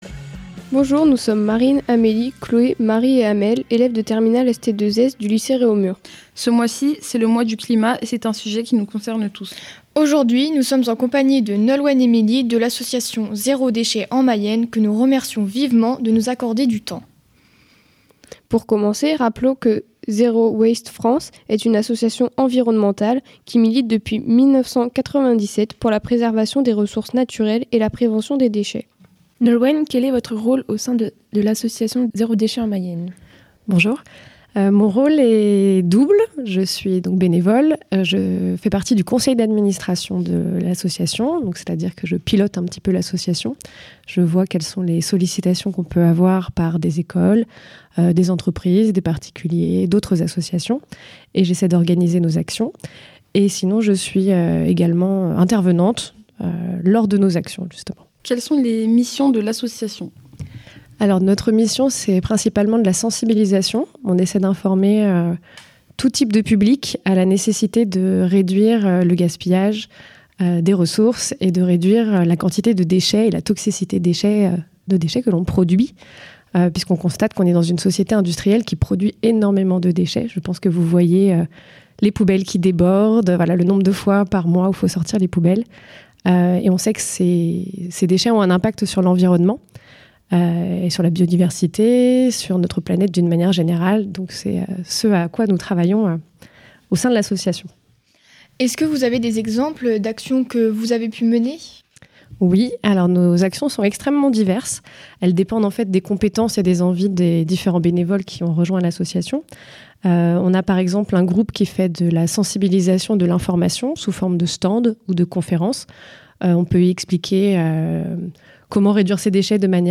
interview (à partir de la 53ème minute de l'émission)